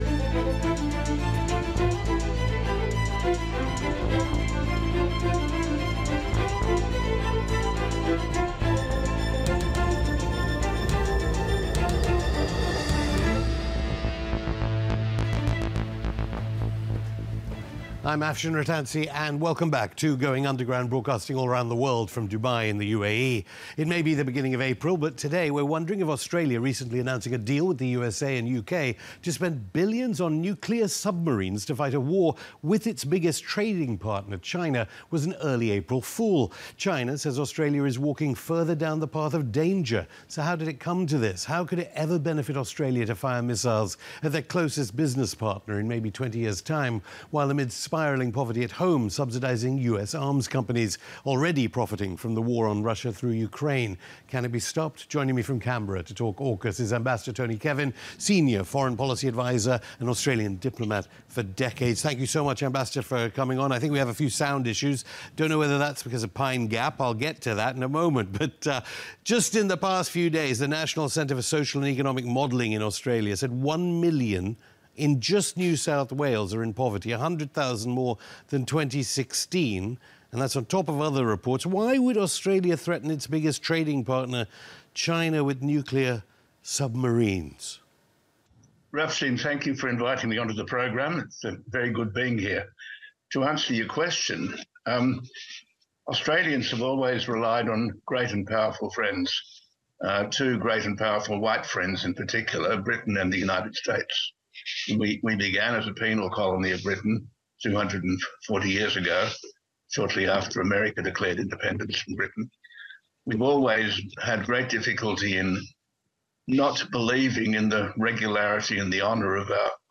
On this episode of Going Underground, we speak to former senior diplomat for over three decades for Australia, Ambassador Tony Kevin